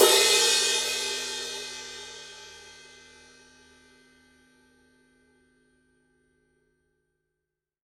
Type Ride
20_ride_edge.mp3